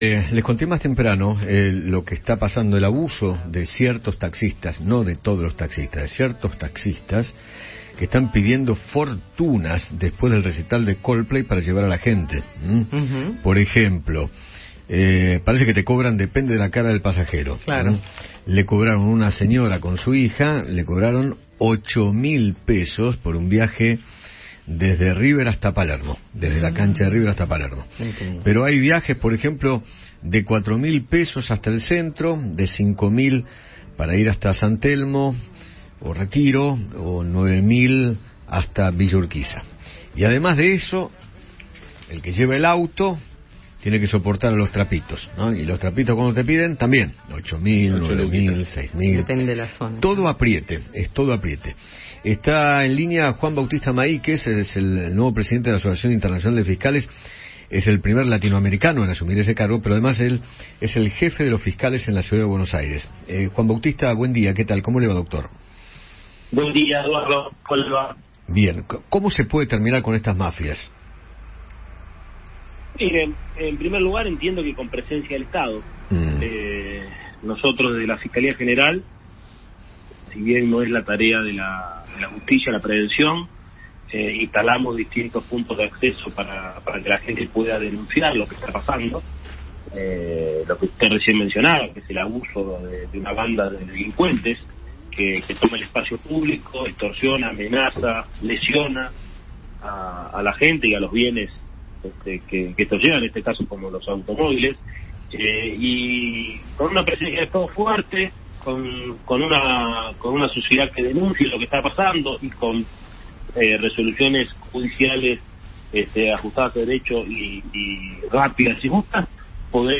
Juan Bautista Mahiques, jefe de los fiscales de CABA, conversó con Eduardo Feinmann sobre los reclamos por los “trapitos” y el abuso de los taxistas que se acercan a la salida del recital de Coldplay a pedir fortuna para llevar a la gente.